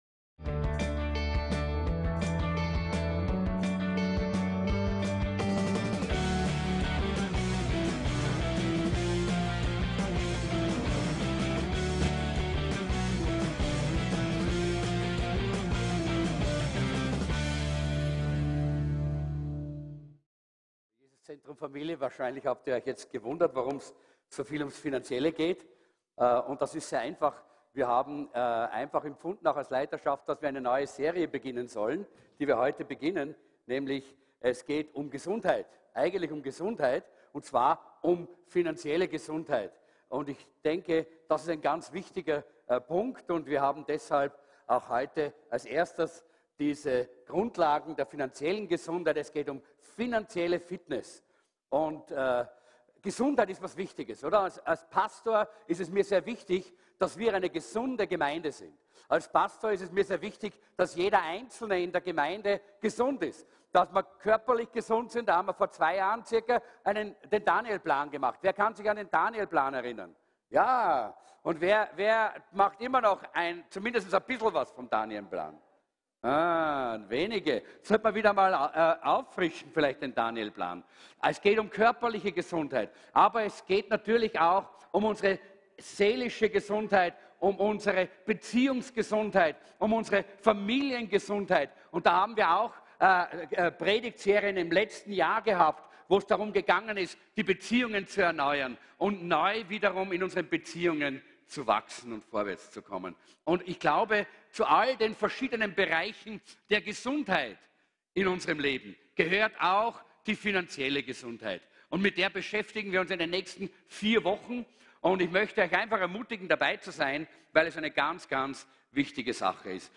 VCC JesusZentrum Gottesdienste (audio) Podcast